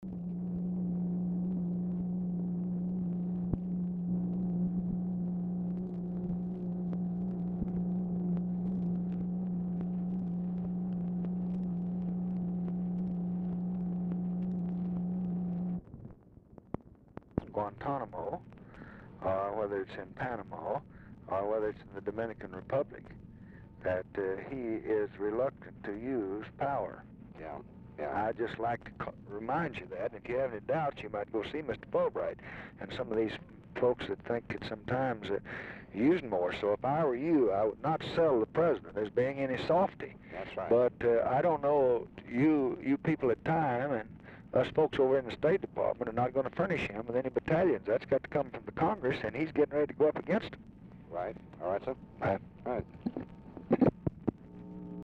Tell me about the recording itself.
Oval Office or unknown location Dictation belt